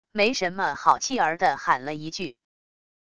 没什么好气儿的喊了一句wav音频